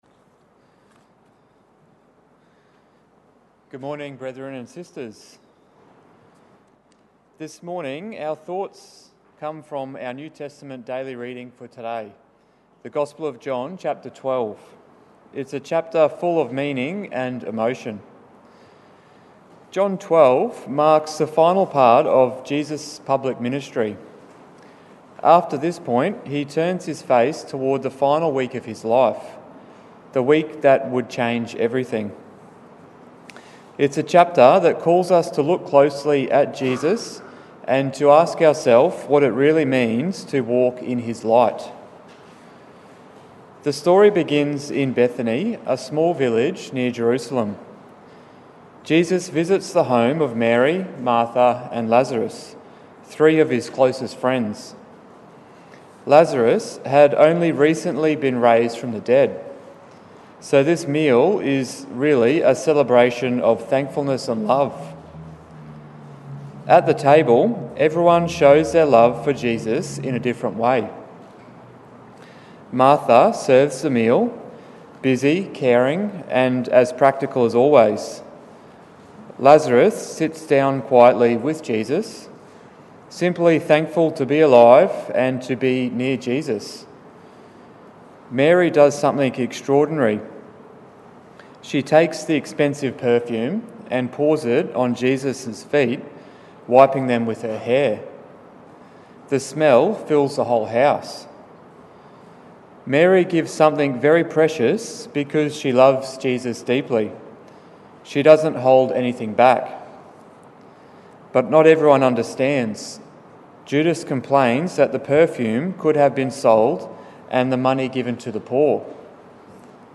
Exhortation - In the Light of Jesus - Know Your Bible